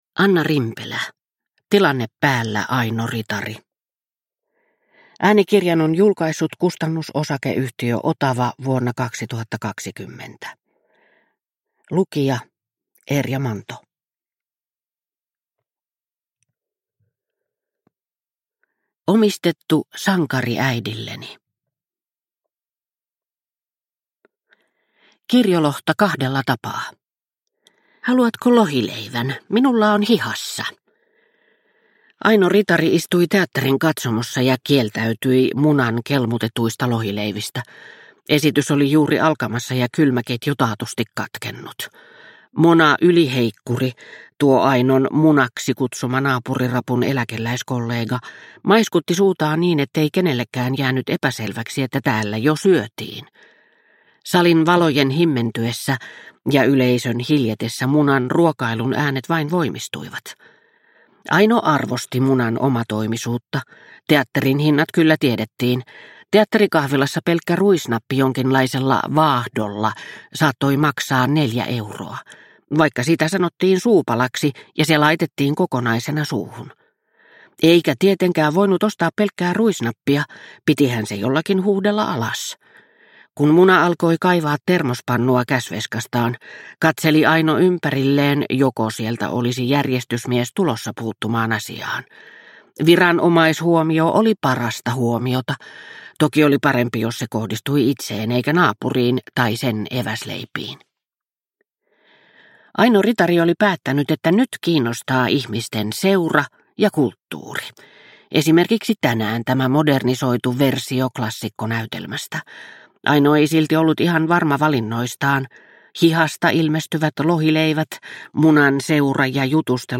Tilanne päällä, Aino Ritari – Ljudbok
• Ljudbok